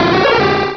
Cri de Pharamp dans Pokémon Rubis et Saphir.
Cri_0181_RS.ogg